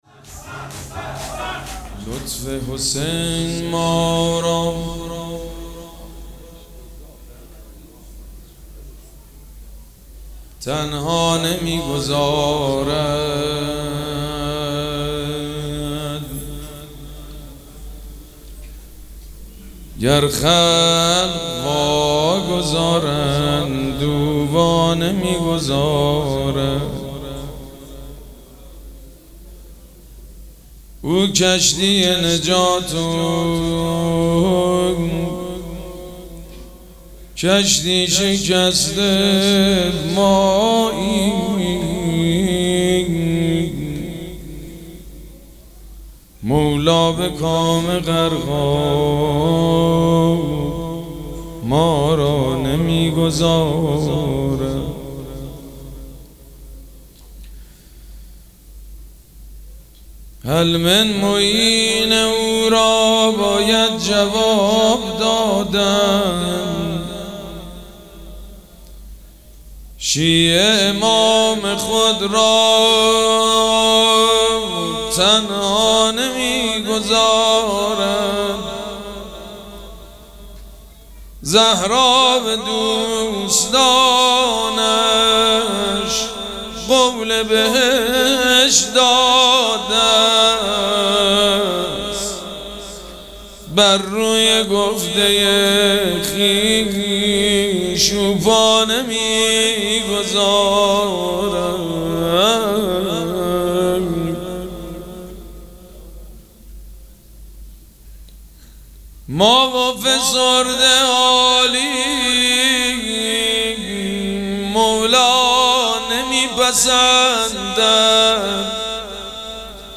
مراسم عزاداری شب شهادت امام حسن مجتبی(ع) ‌‌‌‌‌‌‌‌‌‌پنجشنبه ۹ مرداد ۱۴۰۴ | ۶ صفر ۱۴۴۷ ‌‌‌‌‌‌‌‌‌‌‌‌‌هیئت ریحانه الحسین سلام الله علیها
سبک اثــر شعر خوانی مداح حاج سید مجید بنی فاطمه